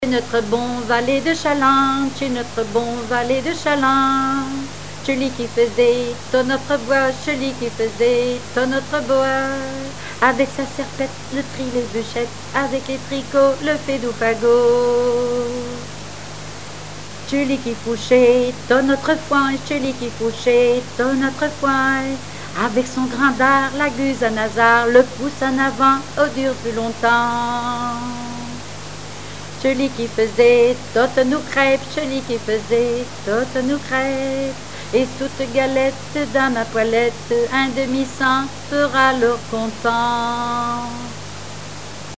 Genre énumérative
répertoire de chansons populaire et traditionnelles
Pièce musicale inédite